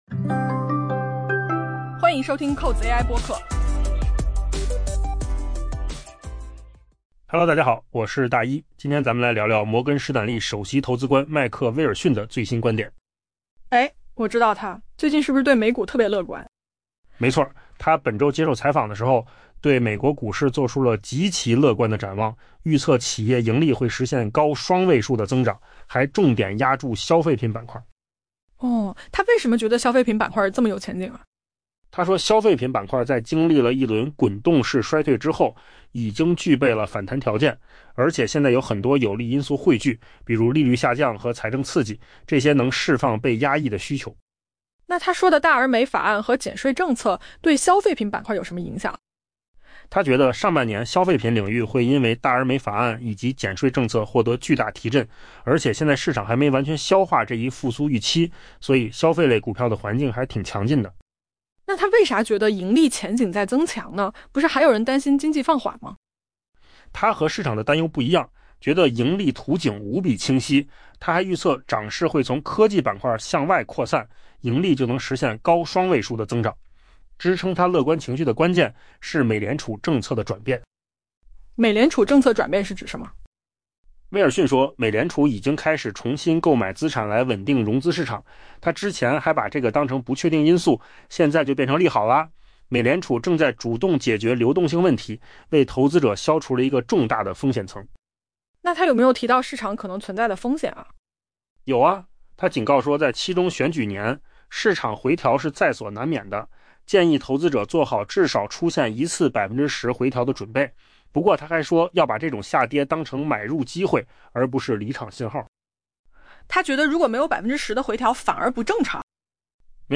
【文章来源：金十数据】AI播客：换个方
AI 播客：换个方式听新闻 下载 mp3 音频由扣子空间生成 本周，摩根士丹利首席投资官迈克·威尔逊 （Mike Wilson） 对美国股市做出了极其乐观的展望。